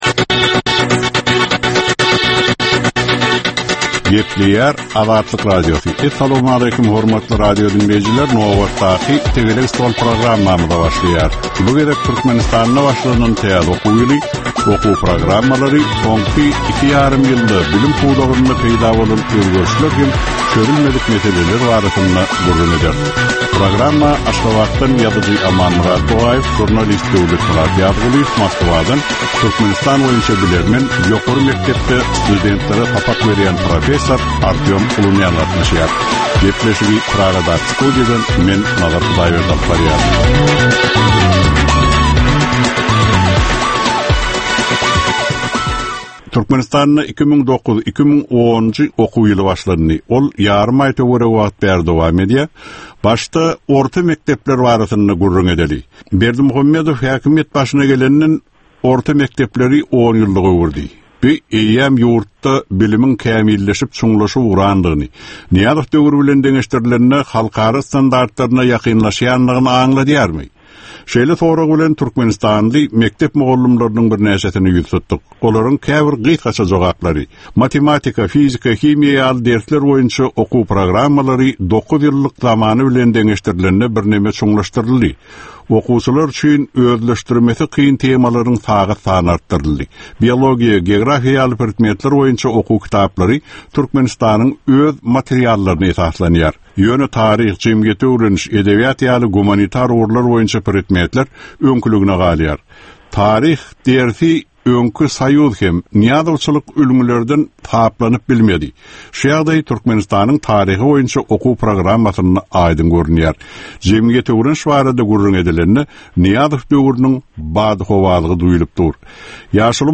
Jemgyýetçilik durmuşynda bolan ýa-da bolup duran soňky möhum wakalara ýa-da problemalara bagyşlanylyp taýyarlanylýan ýörite Tegelek stol diskussiýasy. 25 minutlyk bu gepleşhikde syýasatçylar, analitikler we synçylar anyk meseleler boýunça öz garaýyşlaryny we tekliplerini orta atýarlar.